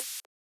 Index of /90_sSampleCDs/300 Drum Machines/Hammon Auto-Vari 64/Hammond Auto-Vari 64 Ableton Project/Samples/Recorded